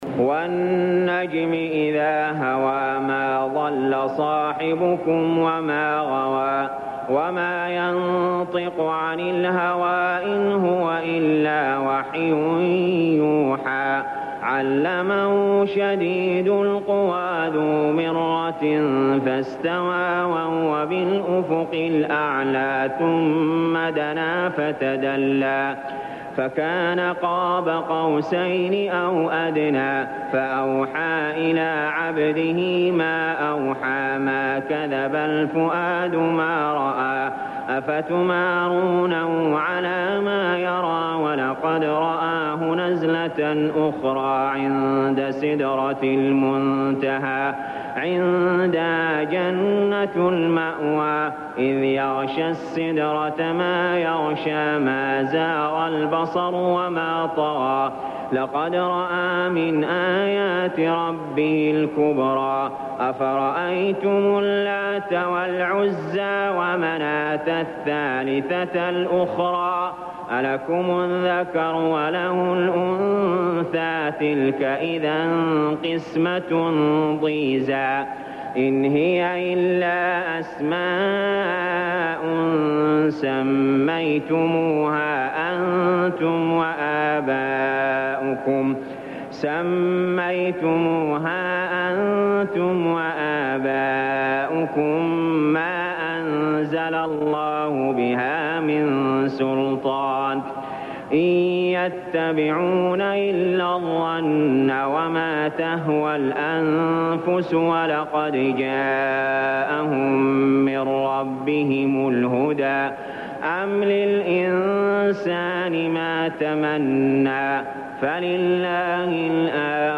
المكان: المسجد الحرام الشيخ: علي جابر رحمه الله علي جابر رحمه الله النجم